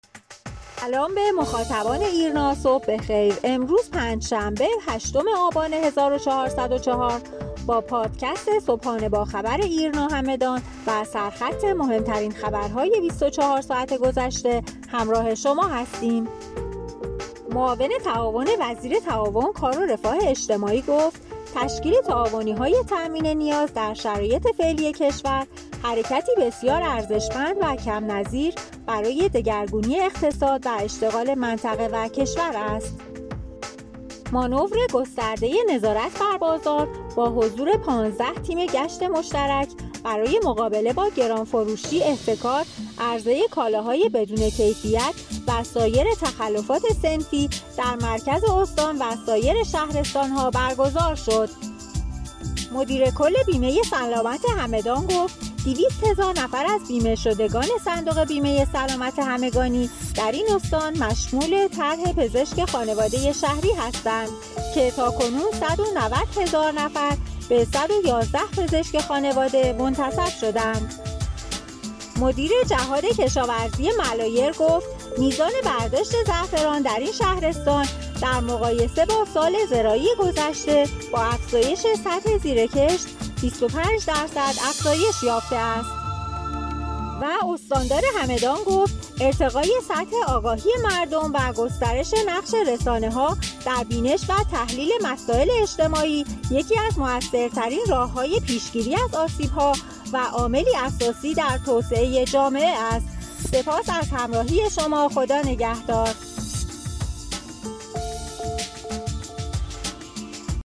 همدان- ایرنا- نظارت بر بازار همدان با حضور ۱۵ تیم، پوشش ۹۵ درصدی پزشک خانواده بین بیمه شدگان سلامت همدان، افزایش ۲۵ درصدی برداشت زعفران در ملایر از مهم ترین اخبار شبانه روز گذشته استان همدان بود. خبرنامه صوتی (پادکست) صبحانه با خبر ایرنا همدان را هر روز ساعت هشت صبح دنبال کنید.